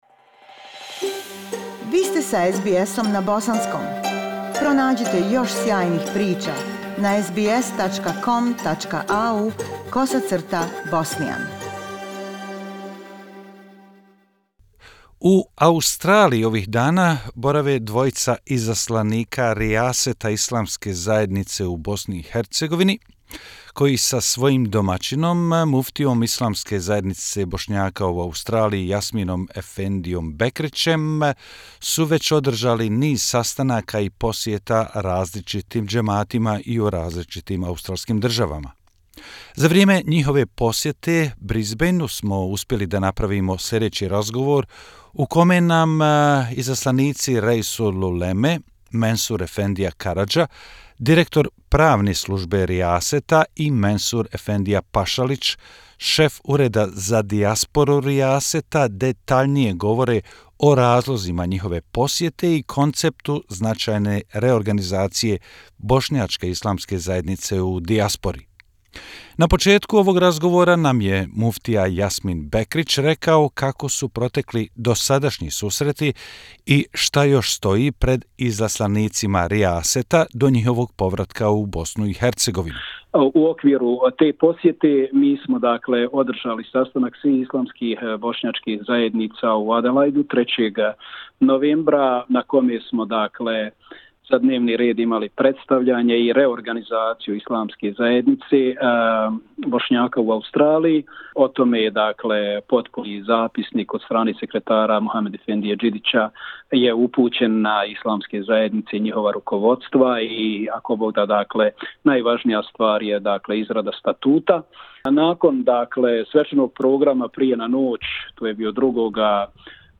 They spoke to us in more detail about the reasons for their visit to Australia and the concept of a significant reorganisation of the Bosniak Islamic community in the diaspora.